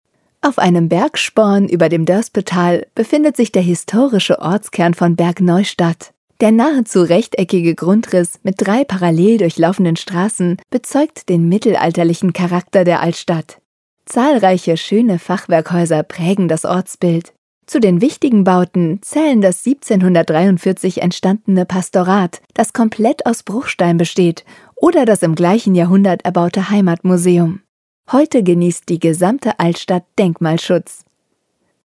audioguide-altstadt-bergneustadt.mp3